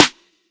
EWYIA_SNR.wav